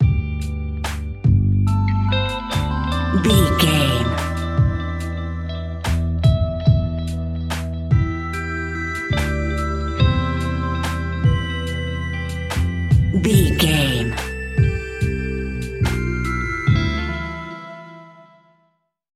Ionian/Major
E♭
laid back
Lounge
sparse
chilled electronica
ambient
atmospheric